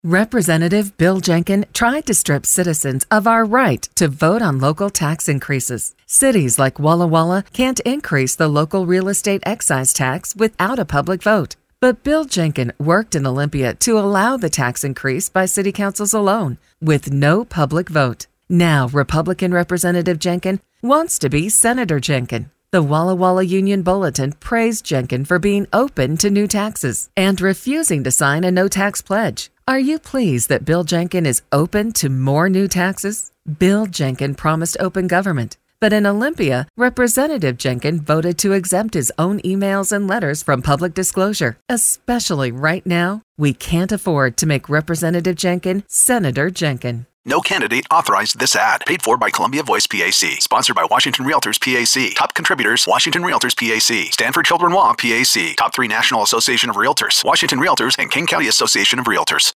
PDC Case 73588 Columbia Voice PAC response radio attach.mp3